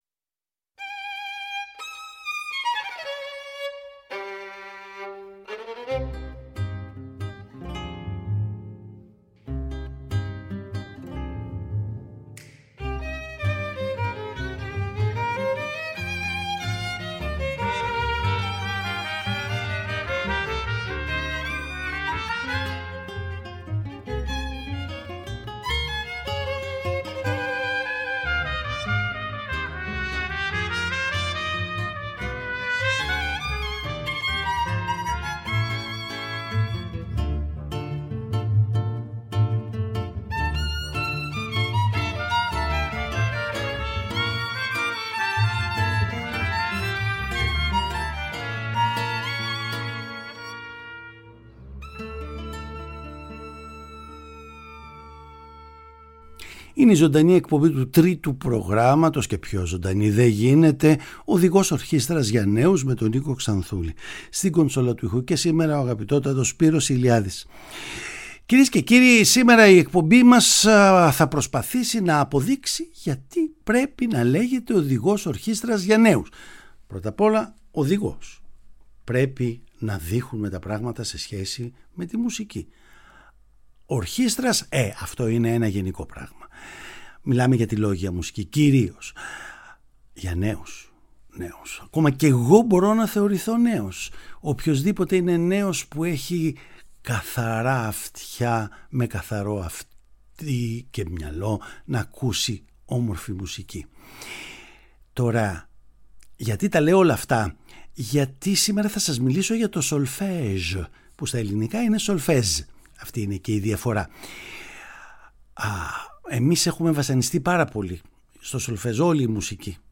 Τα μουσικά παραδείγματα που θα χρησιμοποιηθούν θα αποτελέσουν αφορμή για όμορφα ακούσματα.
Παραγωγή-Παρουσίαση: Νίκος Ξανθούλης